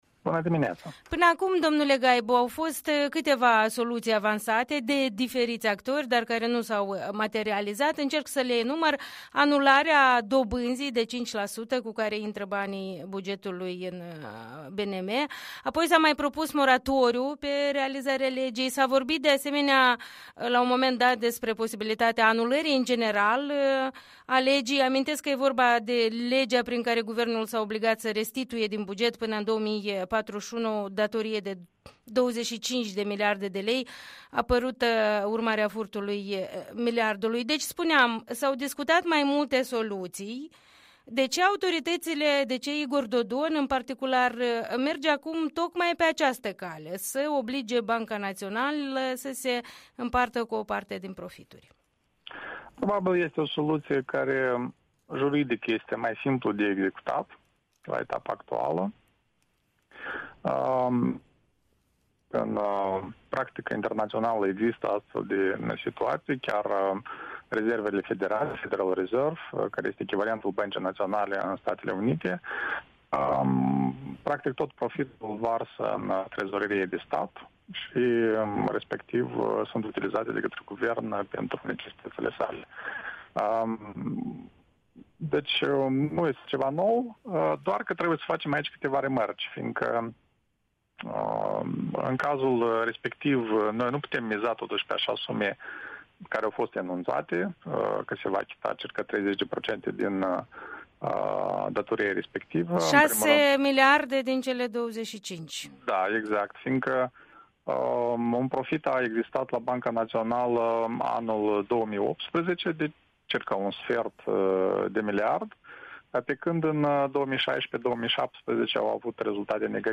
Interviu matinal